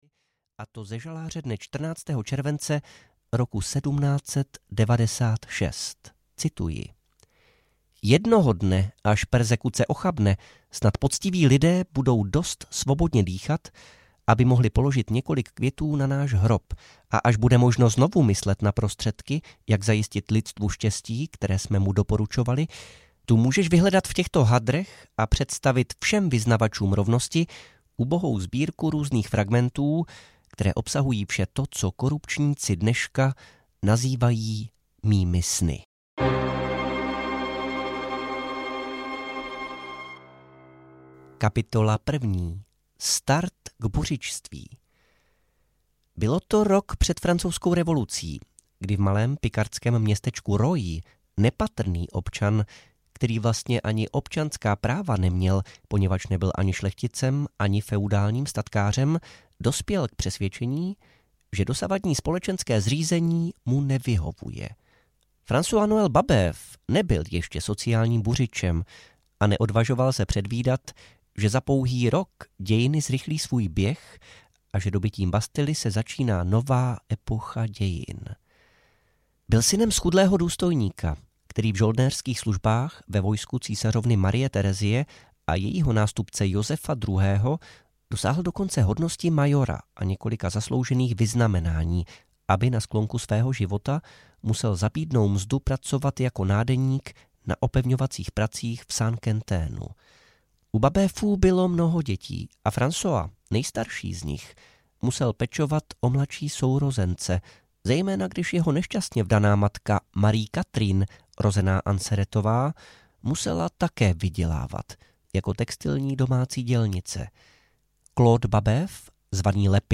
Gracchus Babeuf audiokniha
Ukázka z knihy
gracchus-babeuf-audiokniha